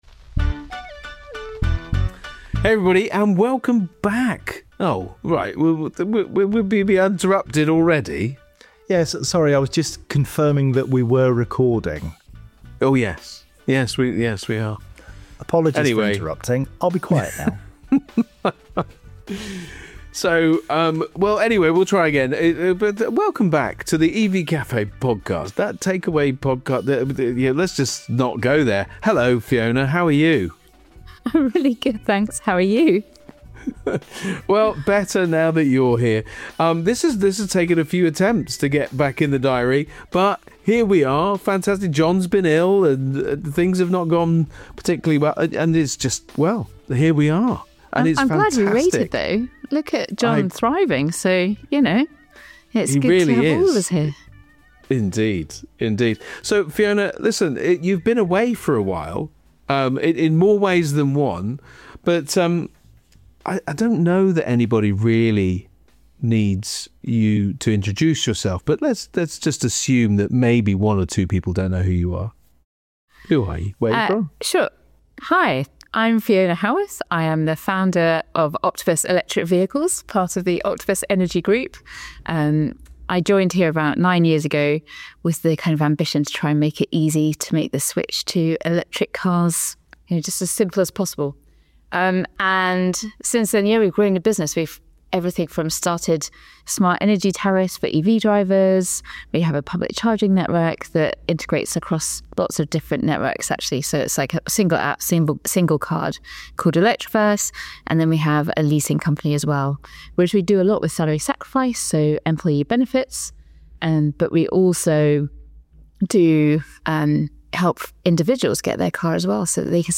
for a wide-ranging conversation that spans electric mobility, leadership, and the importance of living in the present.